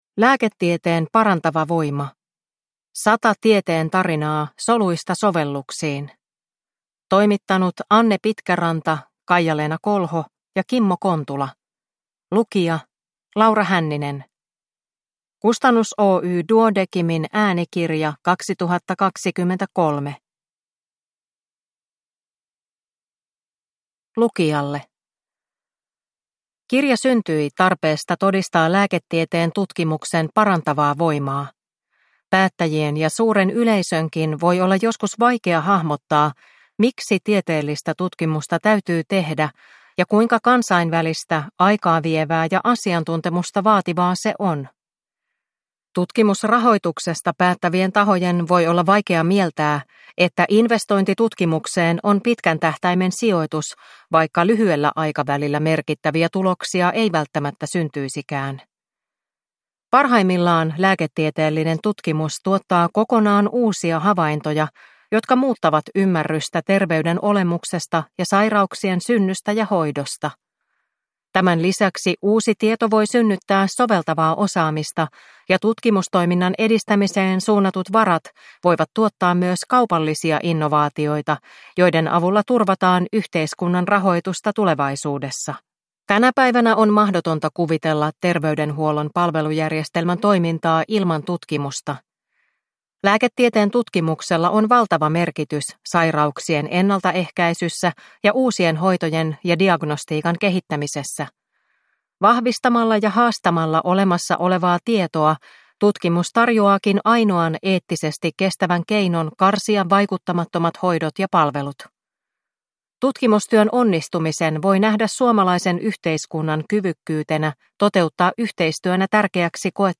Lääketieteen parantava voima – 100 tieteen tarinaa soluista sovelluksiin – Ljudbok